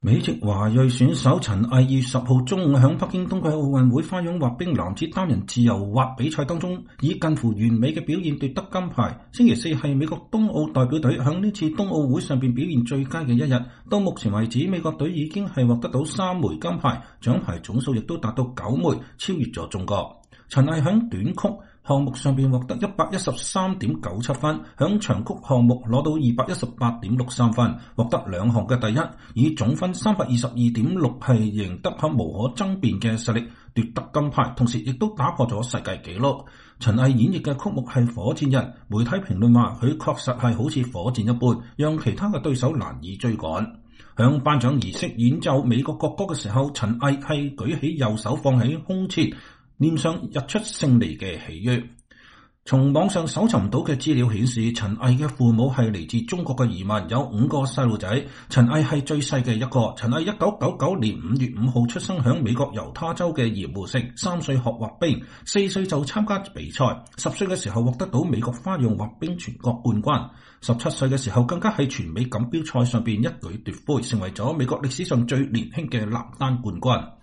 美國華裔陳巍冬奧奪冠破紀錄頒獎儀式手置前胸聆聽國歌
在頒獎儀式演奏美國國歌時，陳巍舉起右手放在胸前，臉上溢出勝利的喜悅。